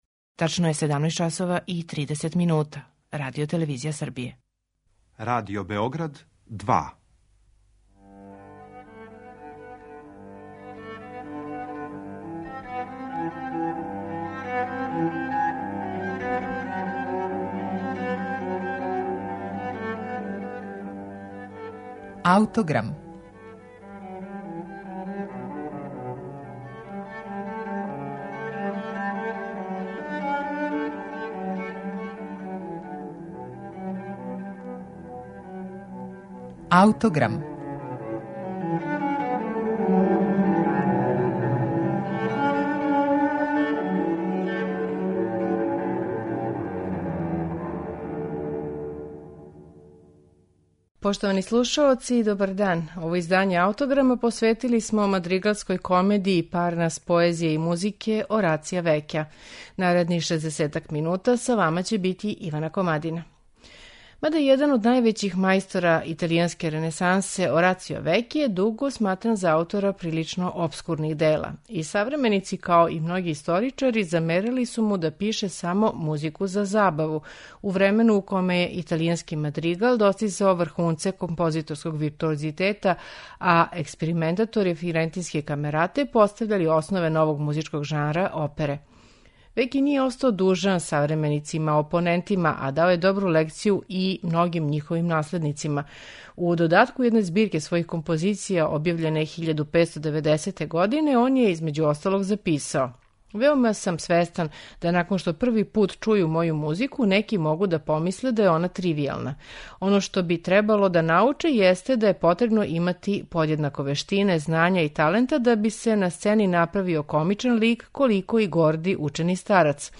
Векијева четири обимна дела, најчешће називана мадригалским комедијама, заправо су збирке краћих комада драматуршки повезаних интересантним заплетом, међу којима се могу разазнати и они озбиљни и они комични.